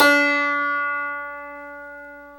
Index of /90_sSampleCDs/Club-50 - Foundations Roland/PNO_xTack Piano/PNO_xTack Pno 1D